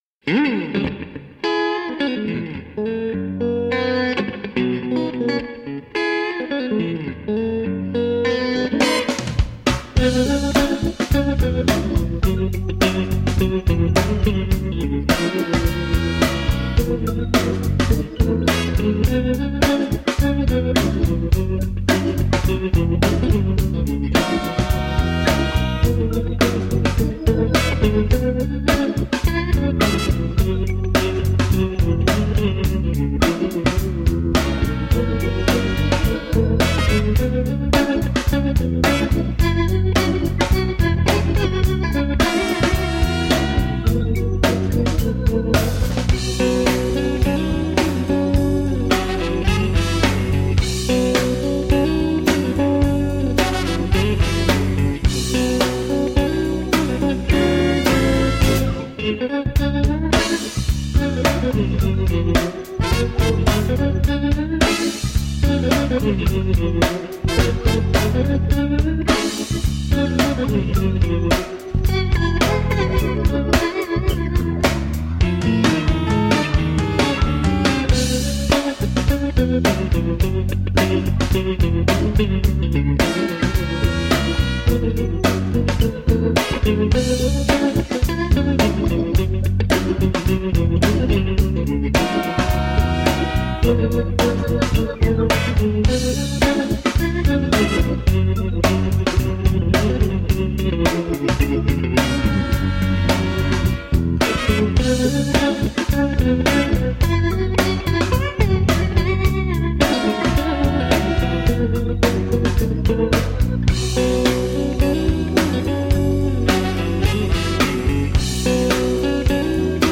Guitar-driven, groove-oriented feel-good jazz/r&b tunes.
Tagged as: Jazz, Other